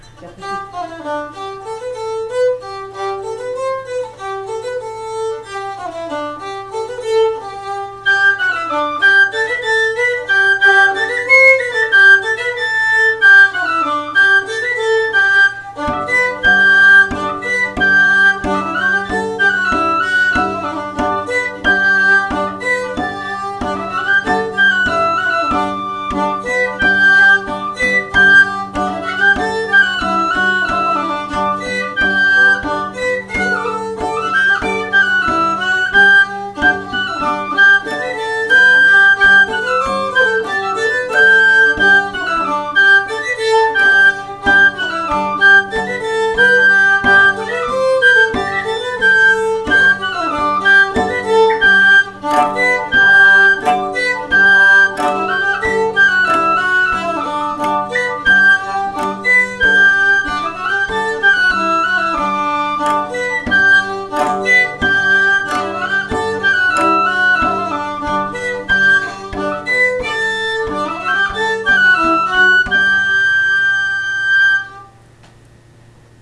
Aire culturelle : Couserans
Genre : morceau instrumental
Instrument de musique : violon ; flûte à trois trous ; tambourin à cordes
Danse : ramelet